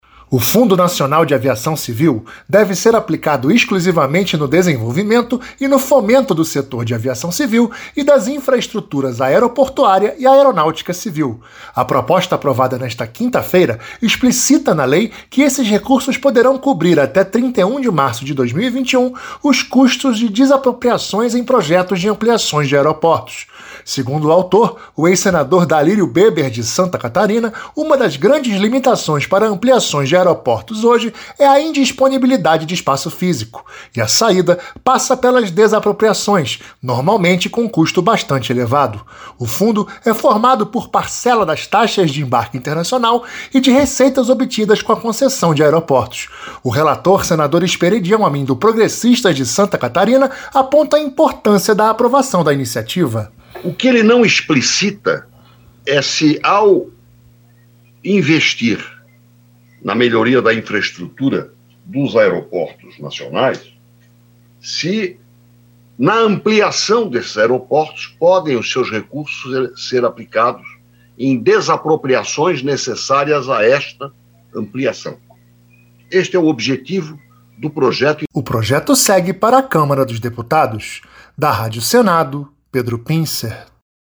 Mais detalhes com o repórter